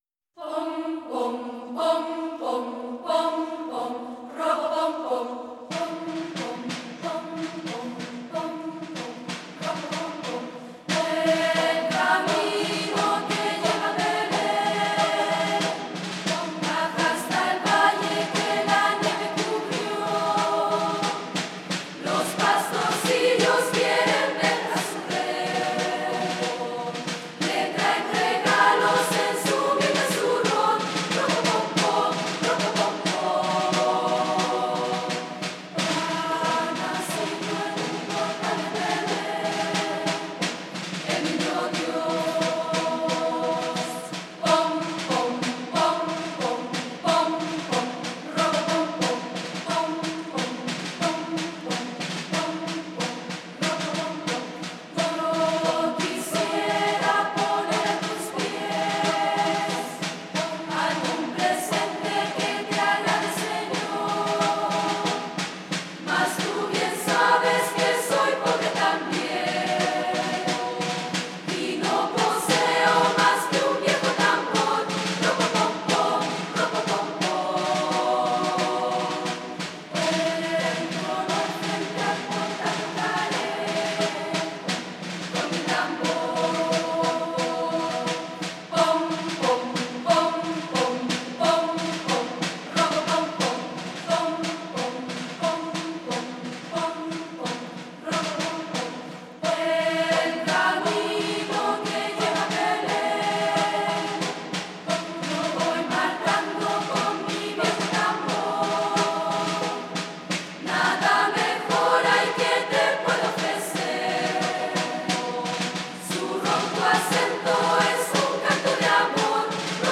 Interpretación musical
Canto
Música religiosa